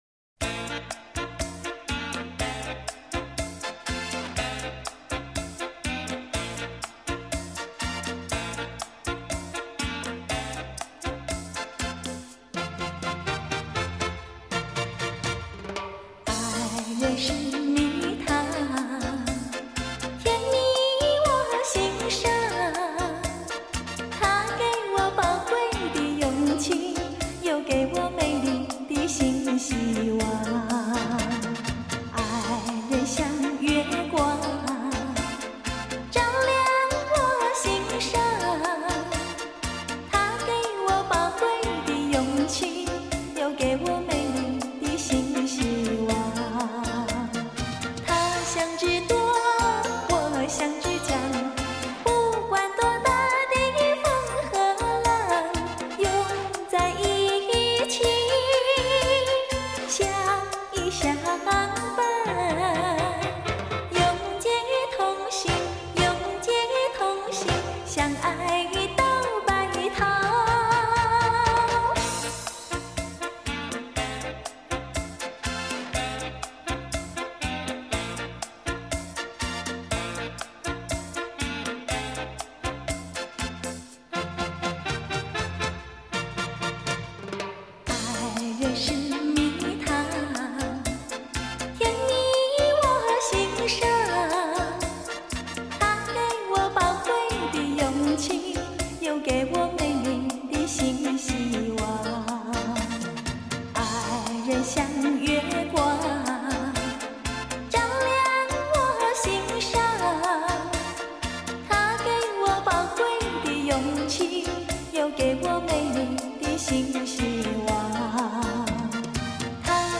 18首恰恰组曲